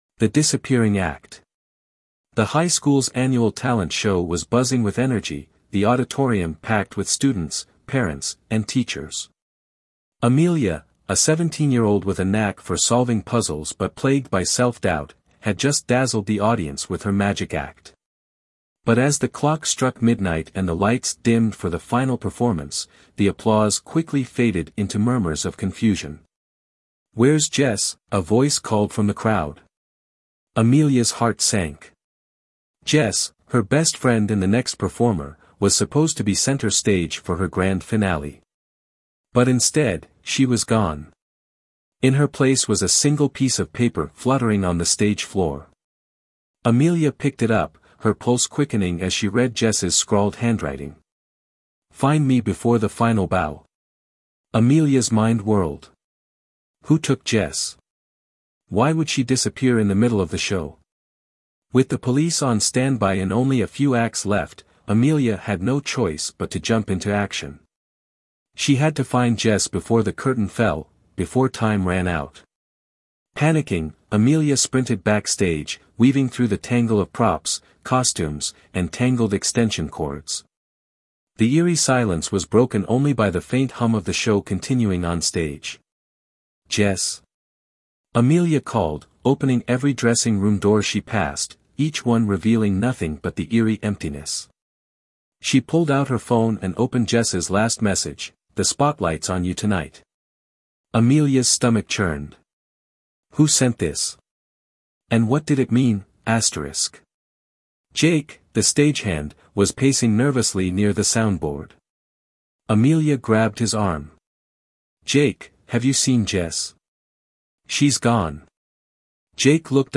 Thanks AI